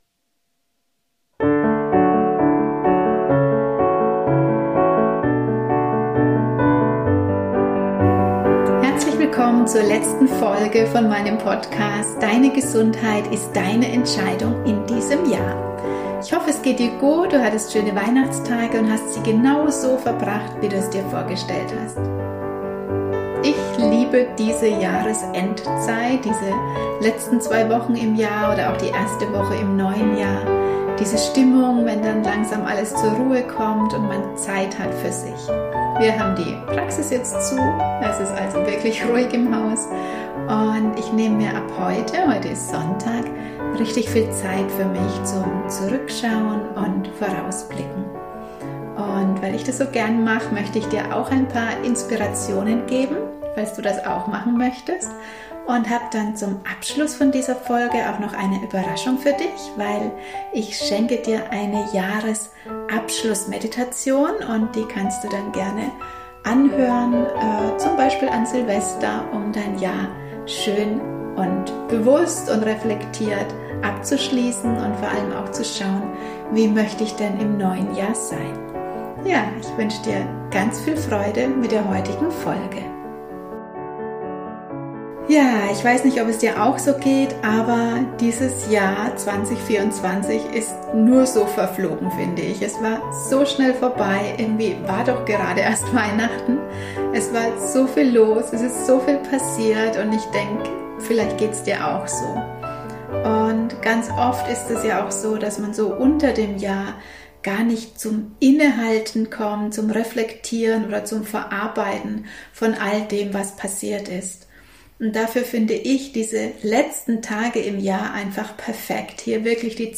Musik Meditation: SEOM Meditationsmusik Instrumental